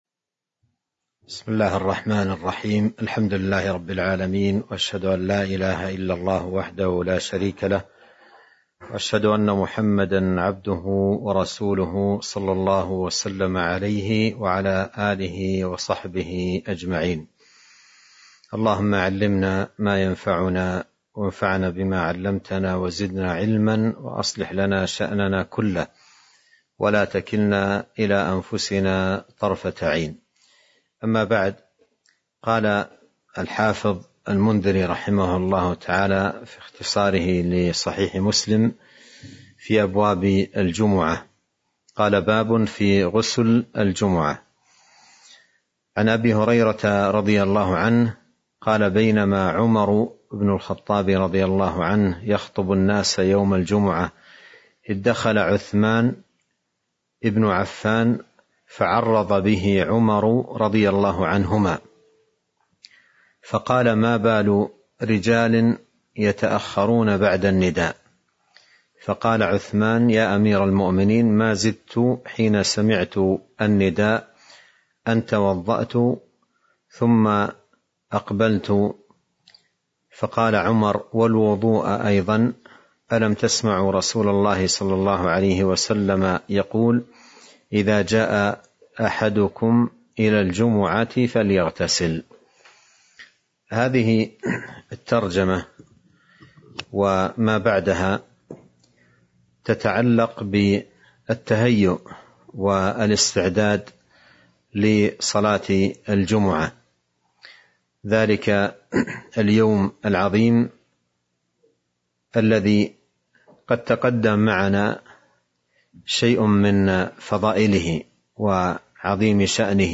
تاريخ النشر ١٥ جمادى الآخرة ١٤٤٢ هـ المكان: المسجد النبوي الشيخ